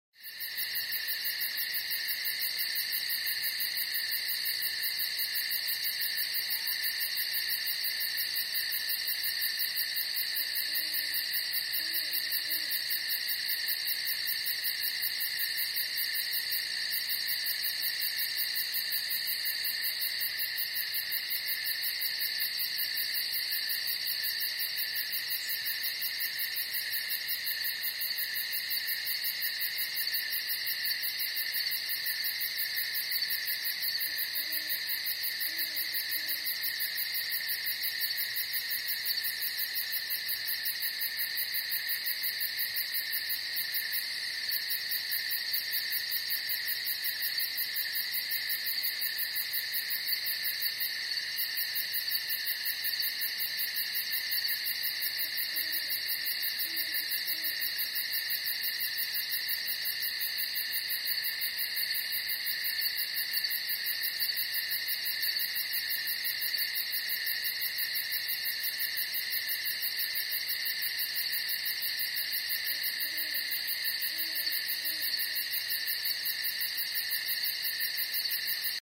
Звуки стрекотания
Много сверчков вокруг